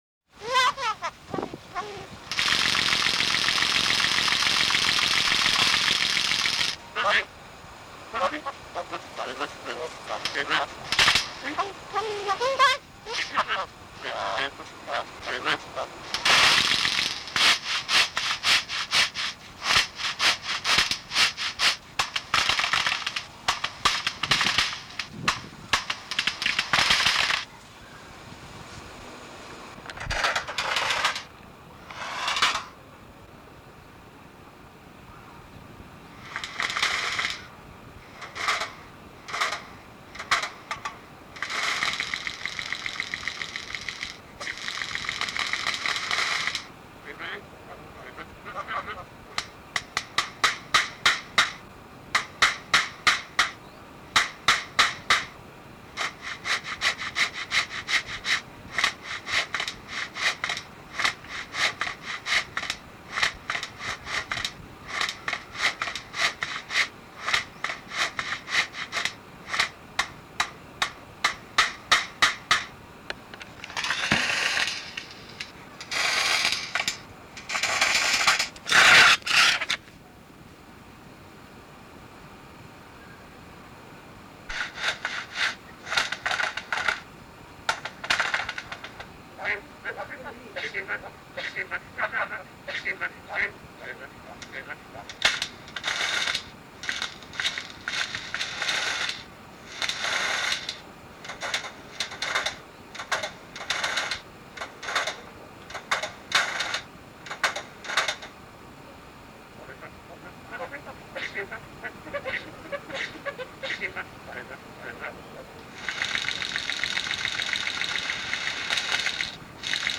Some animals: Blu, Peaches, Max, Sparkie Williams, Alex, and a Fawn Breasted Bowerbird that lives by a construction site in Papua New Guinea.
Fawn-Breasted-Bowerbird.mp3